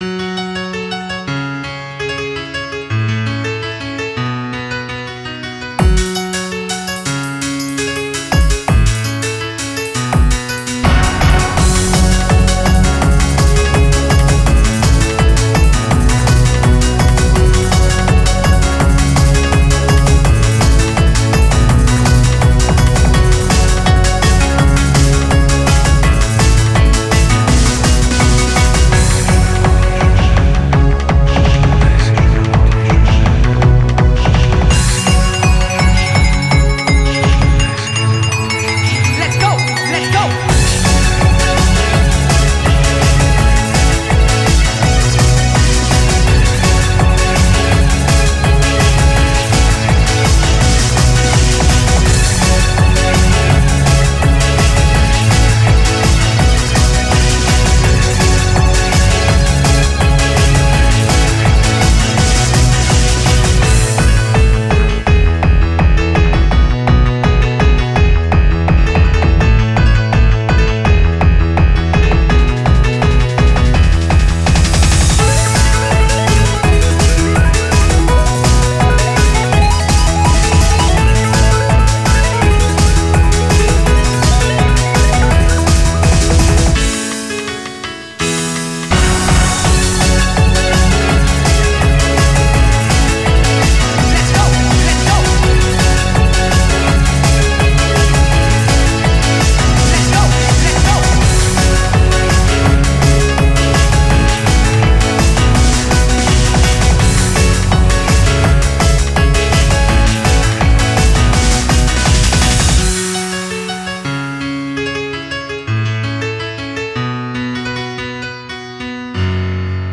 BPM88-166
Audio QualityPerfect (High Quality)